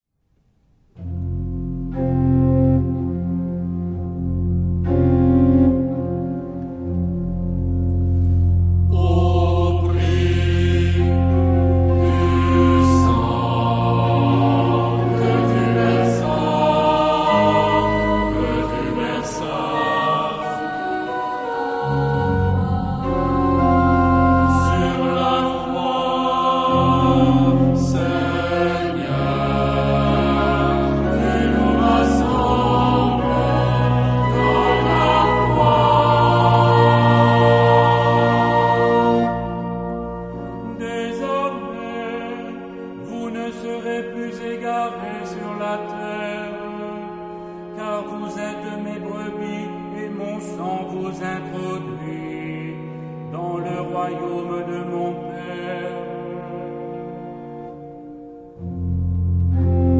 Genre-Stil-Form: geistlich ; Prozession
Charakter des Stückes: ruhig
Chorgattung: SATB  (4-stimmiger gemischter Chor )
Instrumente: Orgel (1)
Tonart(en): F (tonales Zentrum um)